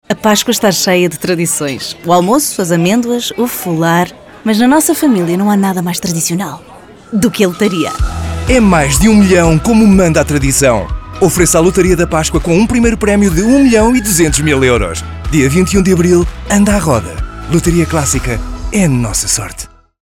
spot de rádio da campanha.